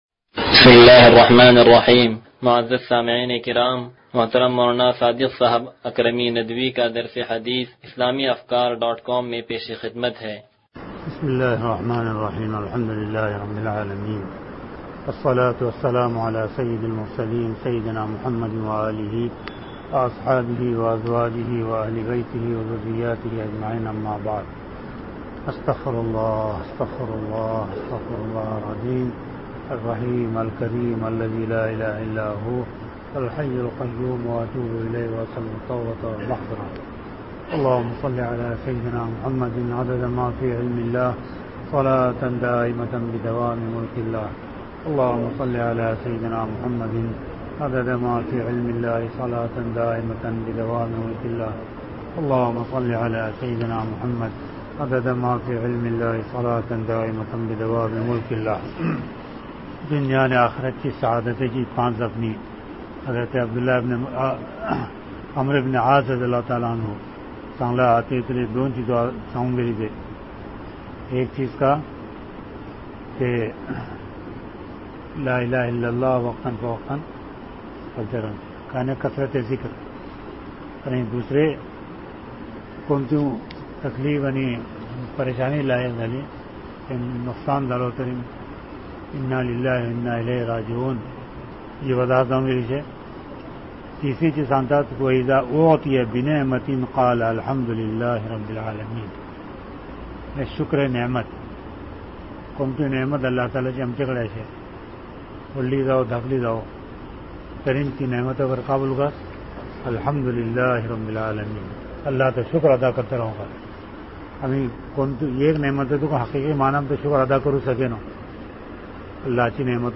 درس حدیث نمبر 0114